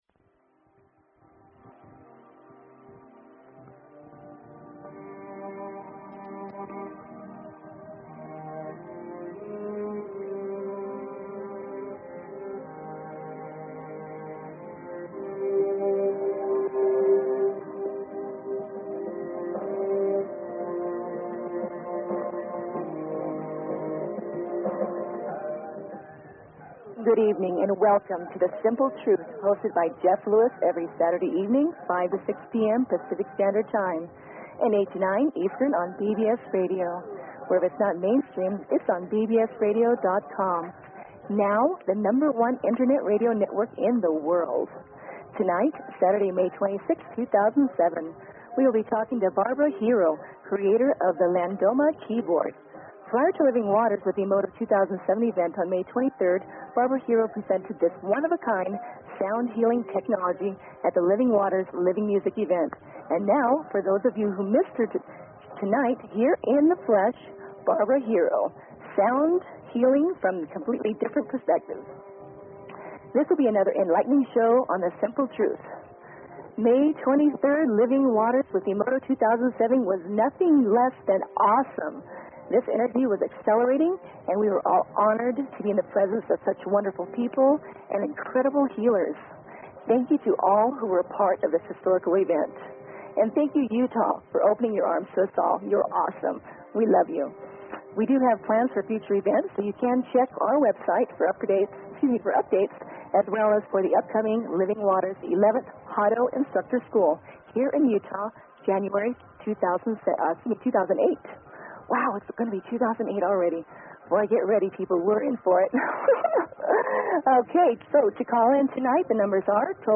Talk Show Episode, Audio Podcast, The_Simple_Truth and Courtesy of BBS Radio on , show guests , about , categorized as